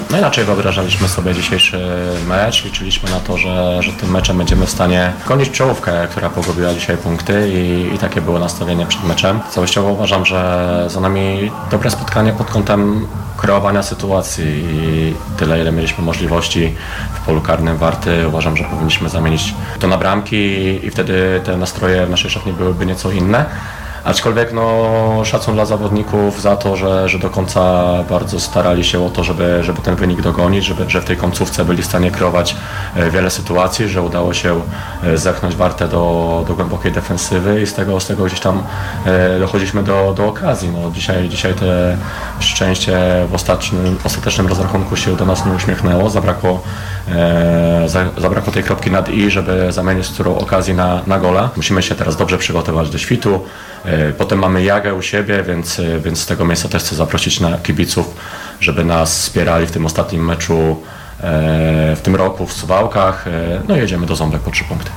Pomeczowy komentarz